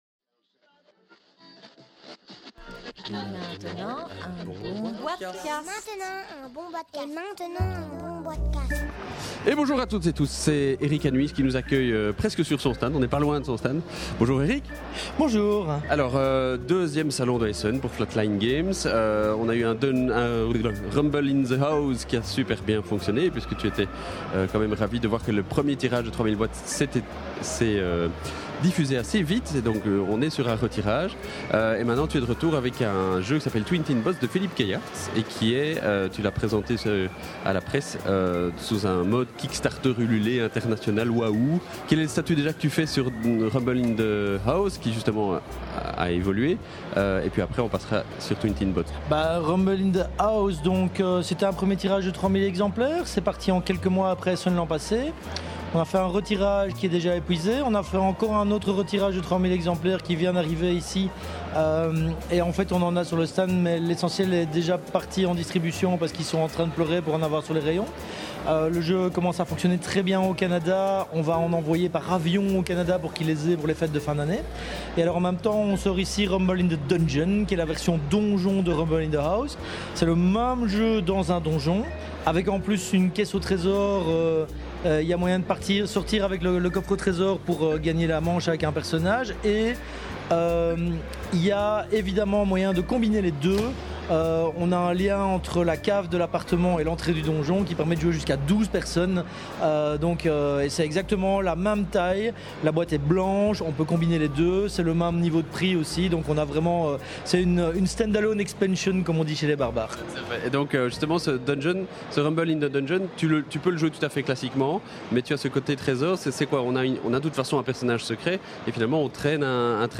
enregistré lors du salon international du jeu de société à Essen – Octobre 2012